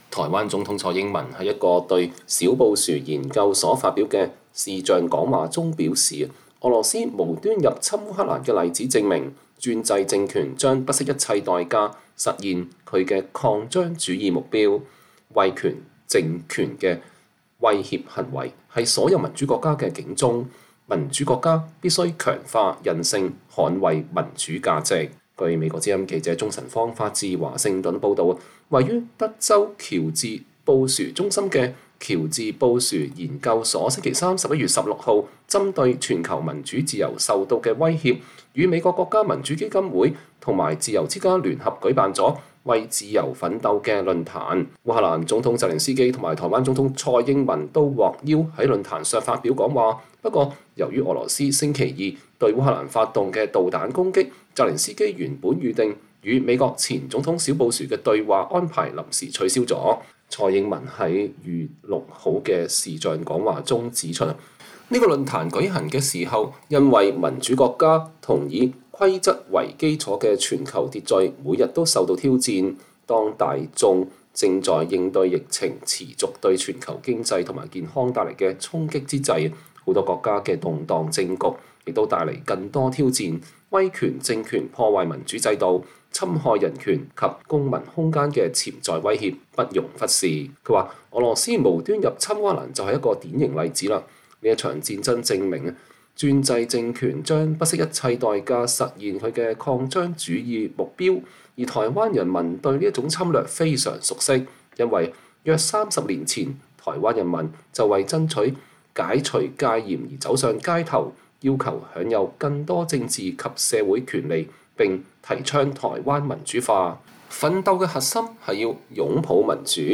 蔡英文對小布殊研究所視頻演說：威權政權的威脅是所有民主國家的警鐘
台灣總統蔡英文在一個對小布殊研究所發表的視頻講話中表示，俄羅斯無端入侵烏克蘭的例子證明，專制政權將不惜一切代價實現其擴張主義的目標，“威權政權的威脅行為是所有民主國家的警鐘”，民主國家必須強化韌性捍衛民主價值。